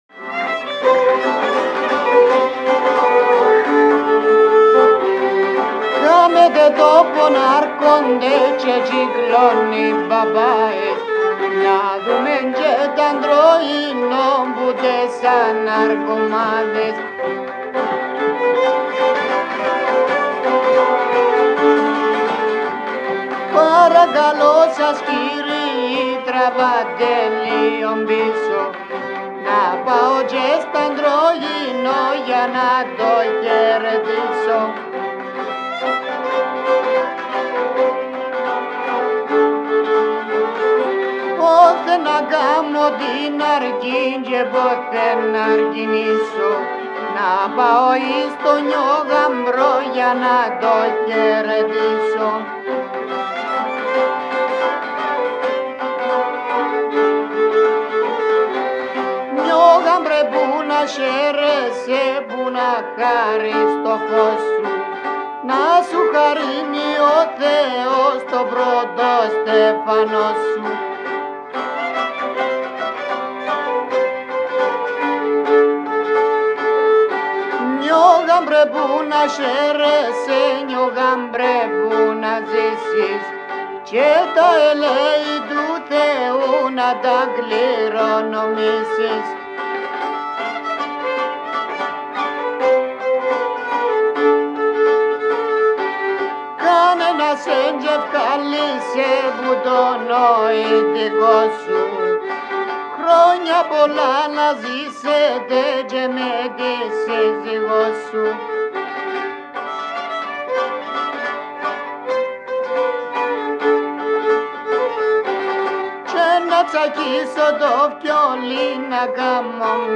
Κύπρος
Συνοδεύουν βιολί, λαούτο